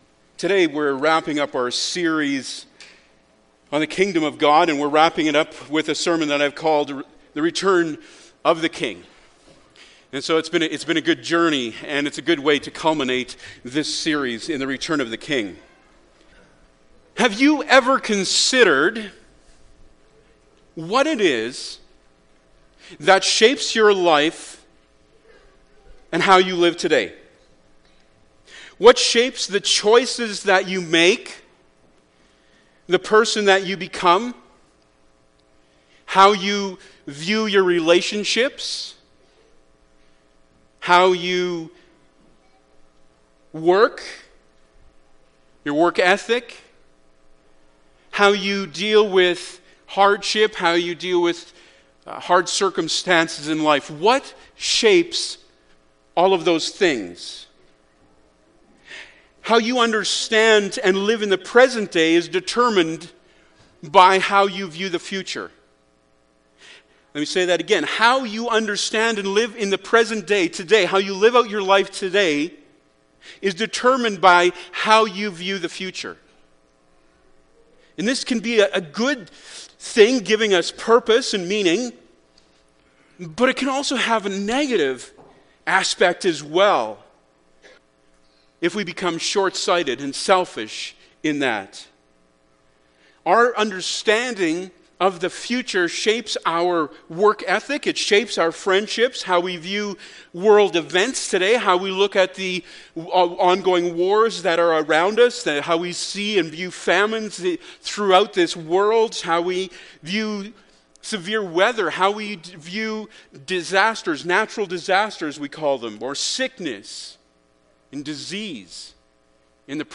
Revelation 21:1-5 Service Type: Sunday Morning Bible Text